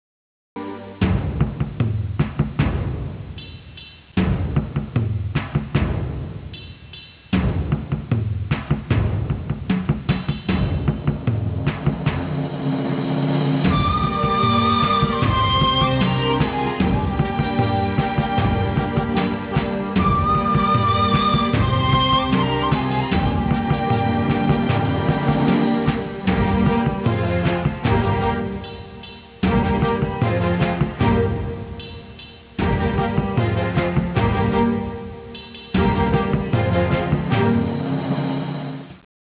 قطعه بی کلام